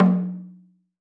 AFRO.TAMB5-S.WAV